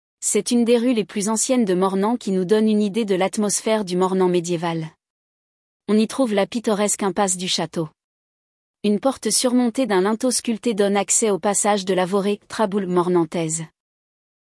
audio guide de la Tour du Vingtain